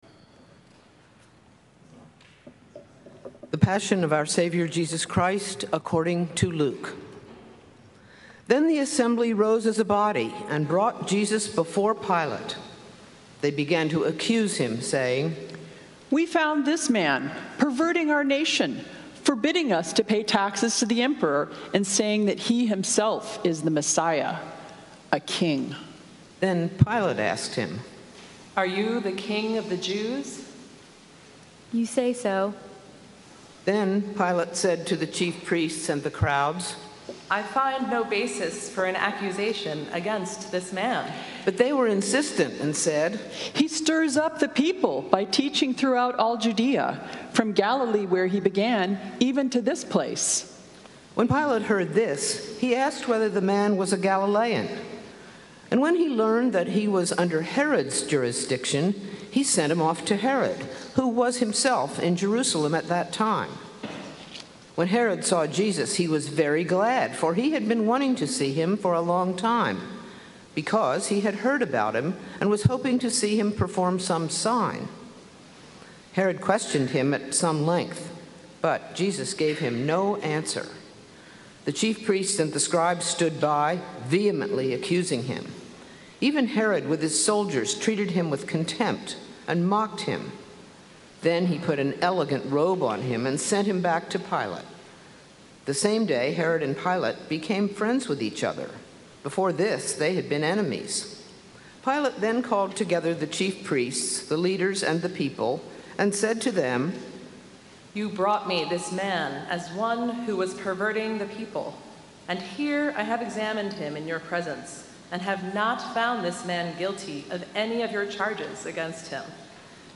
Sermons
St. Columba's in Washington, D.C. Light in the Cracks on Palm Sunday